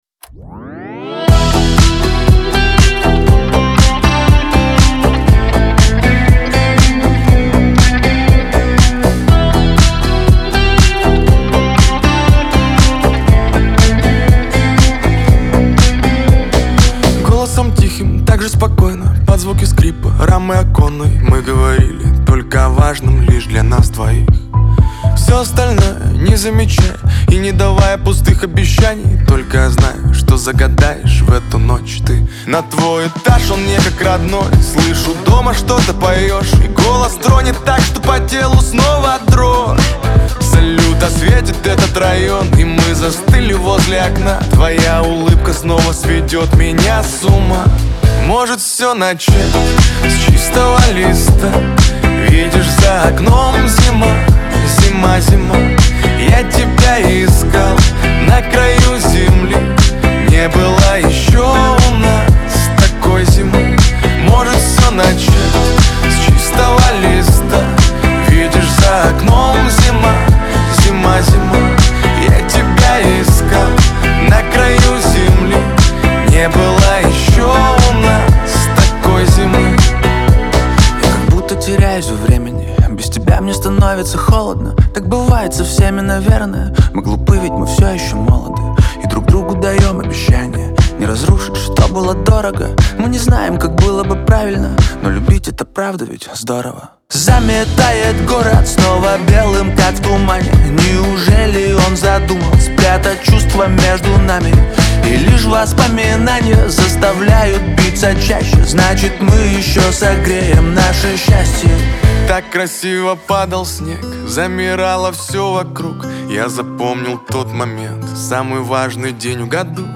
эстрада
pop , диско